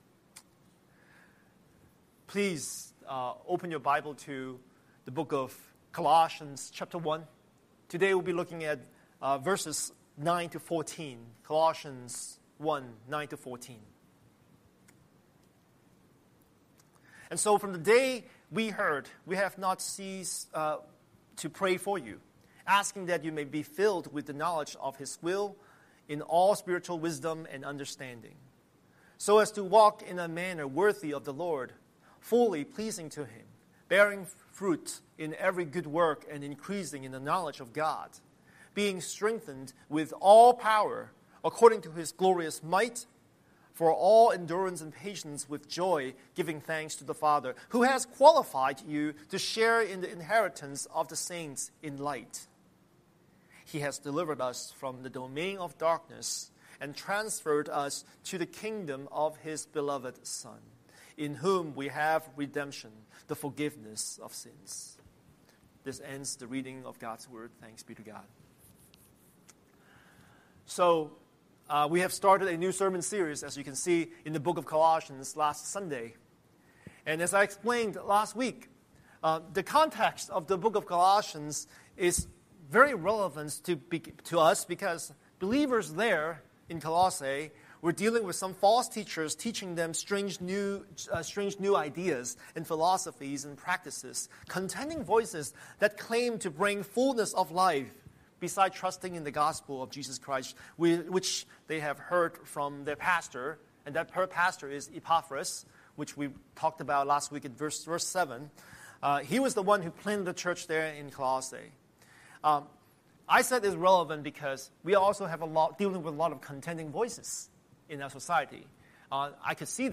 Scripture: Colossians 1:9–14 Series: Sunday Sermon